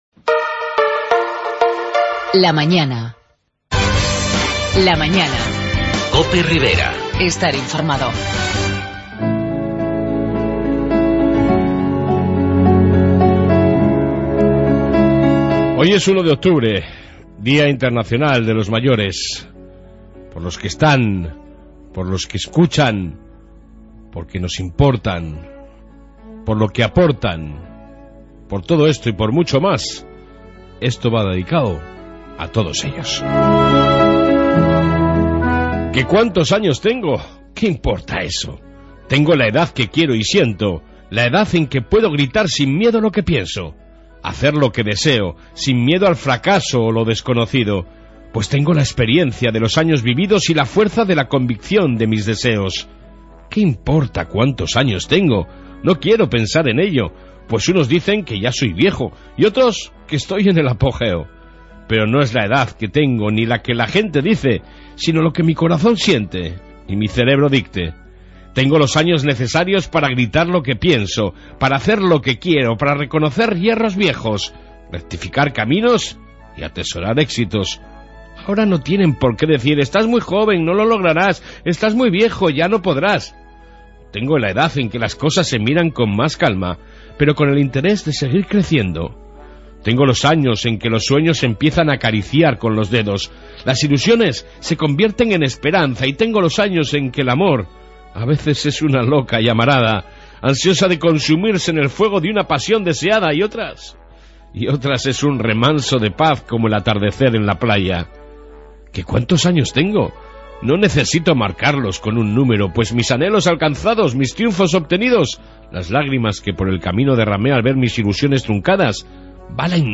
AUDIO: Reflexión-Homenaje en el día de los mayores y Amplia entrevista con el Ex Alcalde de Tudela y ahora parlamentario Luis Casado